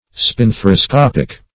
-- Spin*thar`i*scop"ic, a.